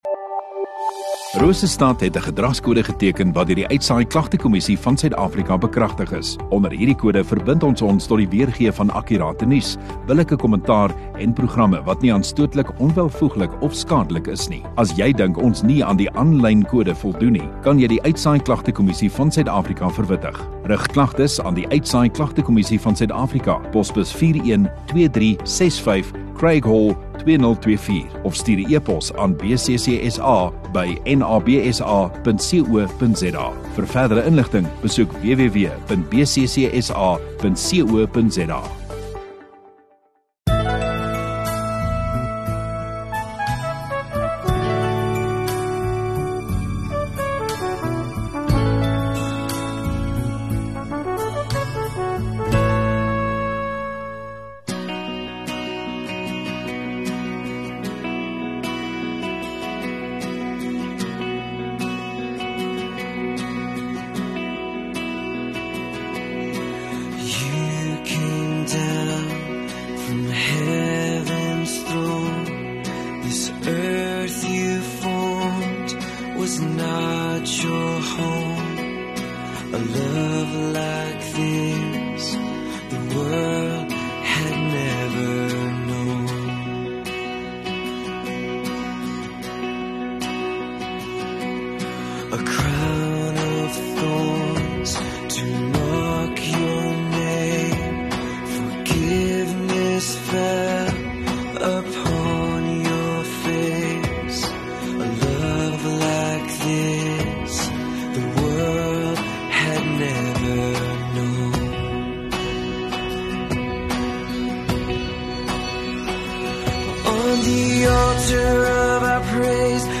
27 Sep Saterdag Oggenddiens